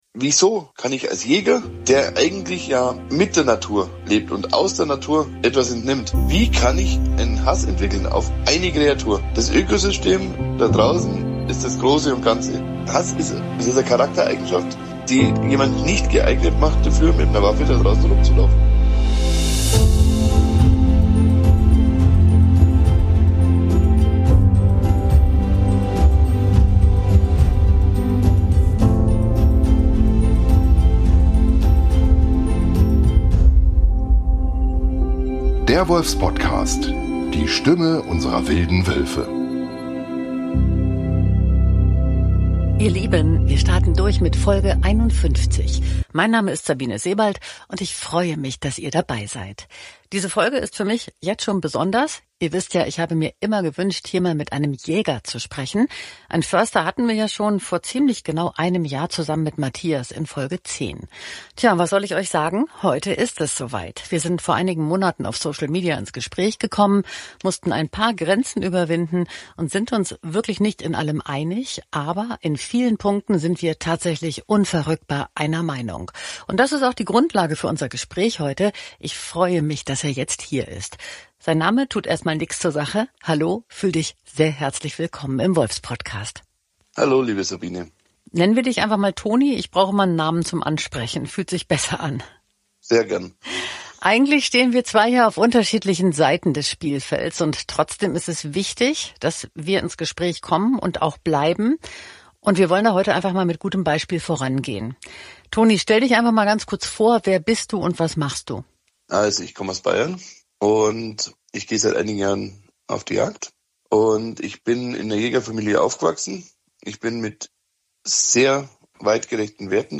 Er benennt offen, wie Desinformation und gezielte Kampagnen – oft aus den eigenen Verbänden – die öffentliche Meinung manipulieren und den Wolf zum Feindbild stilisieren, statt Verantwortung zu übernehmen und Lösungen zu suchen. Es gehört Mut und Haltung dazu, sich so einem Gespräch zu stellen – denn Selbstkritik ist in der Szene selten willkommen. Der erfahrene Jäger fordert: Jagdverbände müssen endlich umdenken, Transparenz schaffen und sich klar gegen illegale Praktiken positionieren.